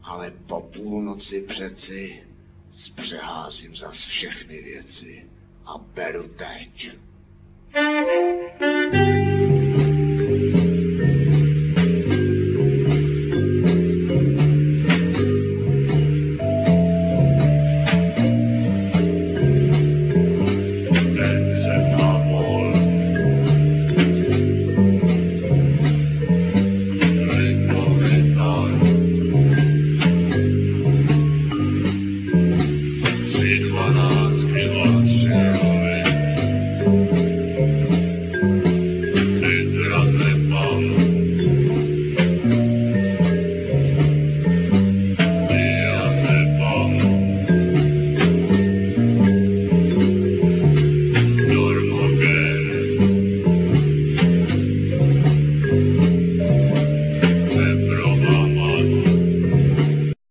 Bass, Vocal
Klaviphone, Vibraphone, Guitar, Vocal
Violin, Vocal
Altsax
Drums
Flute
Theremin